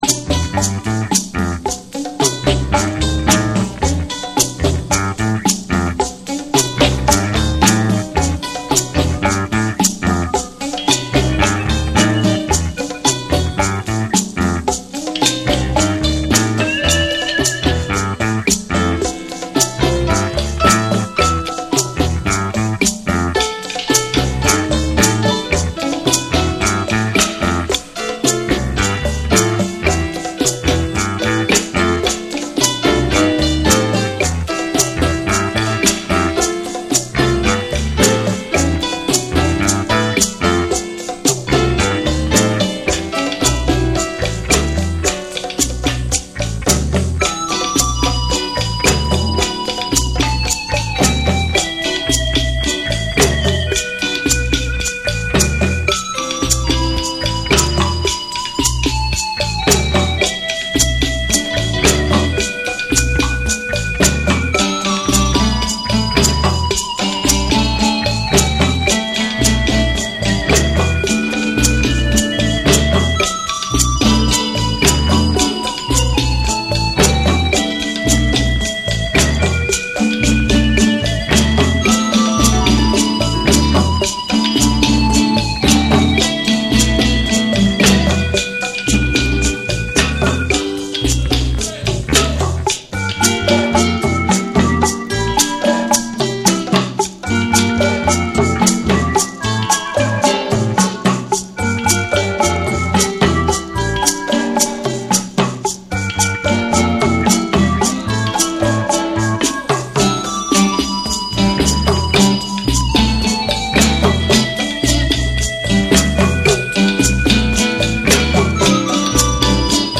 オリエンタルな旋律とダブの深みが融合した異国情緒あふれる
NEW WAVE & ROCK / REGGAE & DUB